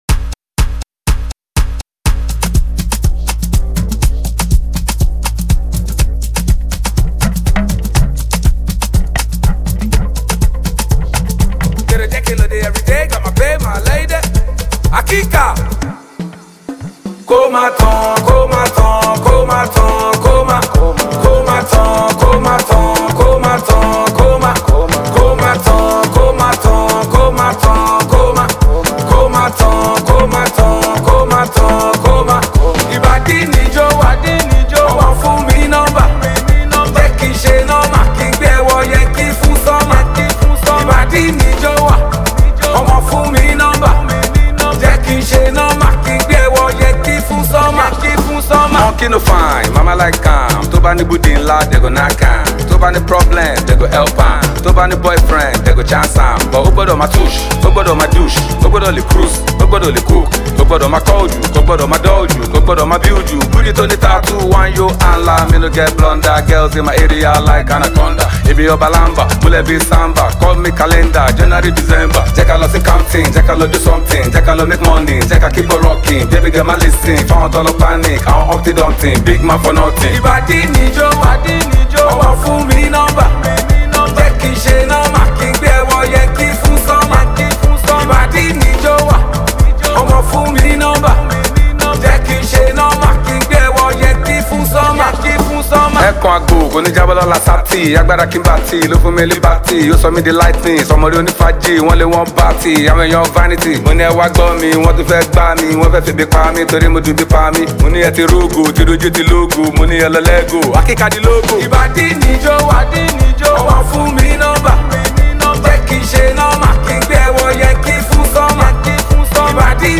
Blending polished production with authentic street energy